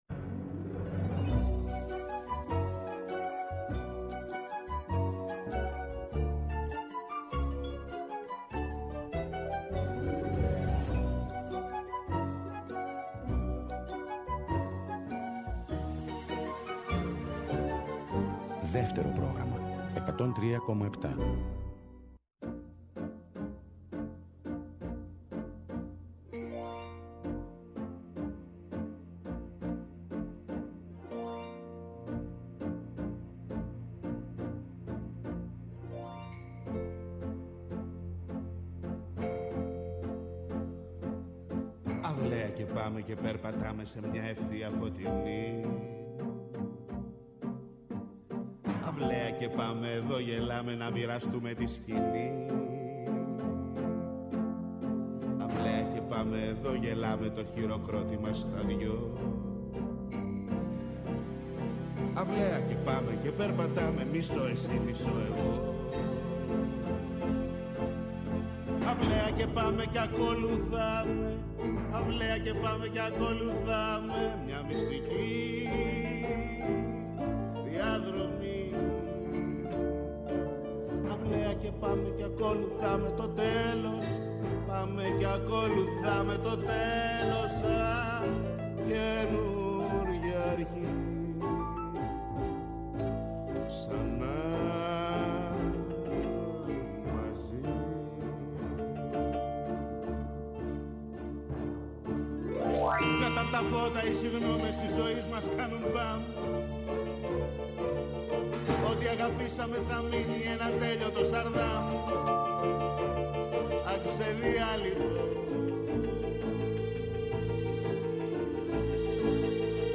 Στο “Μελωδικό Αντίδοτο” oι καινούριες μουσικές κάνουν παρέα με τις παλιές αγαπημένες σε μια ώρα ξεκούρασης καθώς επιστρέφουμε από μια κουραστική μέρα.